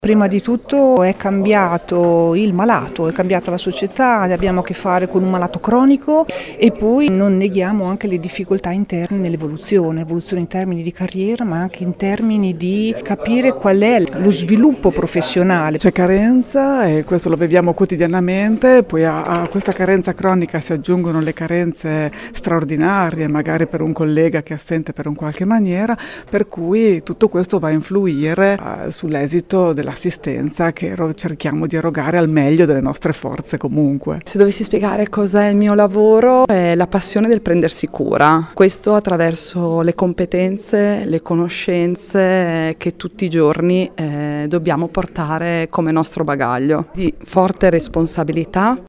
Ed ora le voci di alcune infermiere modenesi: